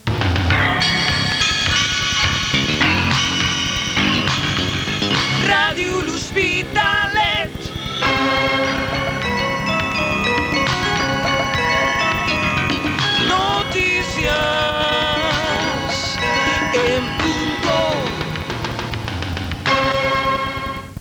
Careta del butlletí de notícies.